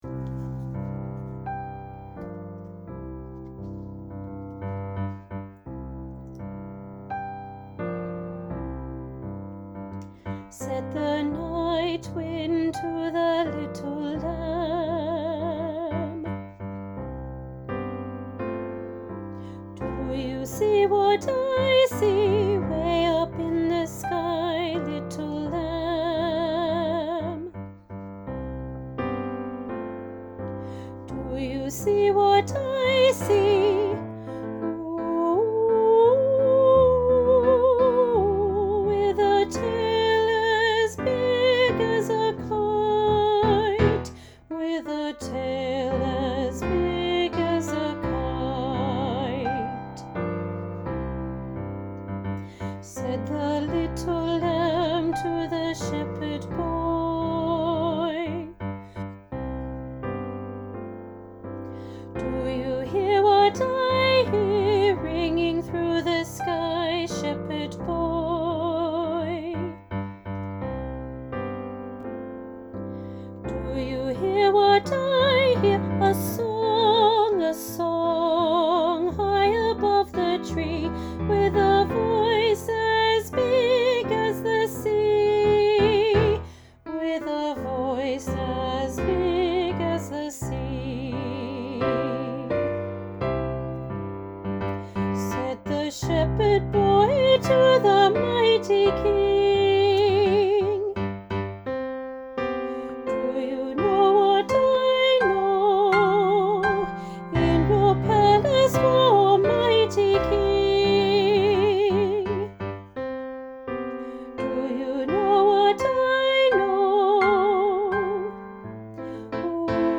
Junior Choir – Do You Hear What I Hear – Sop